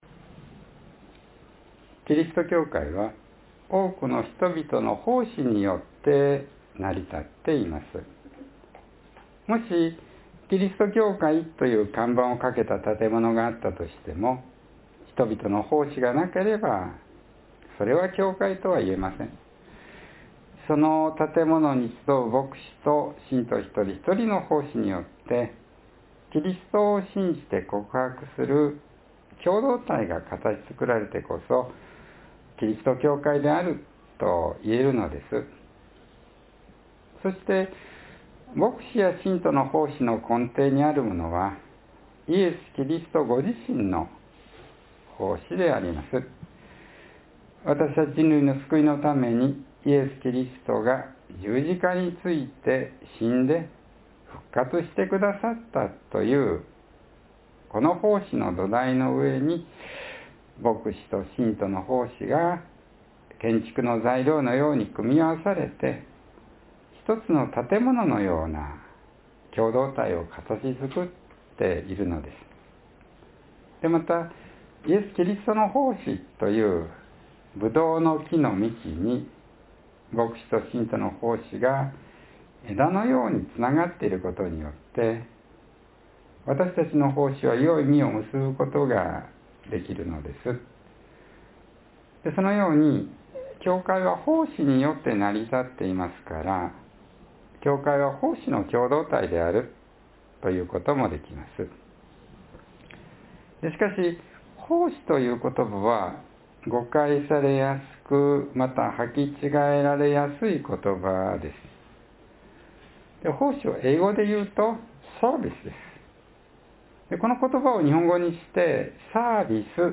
（1月11日の説教より）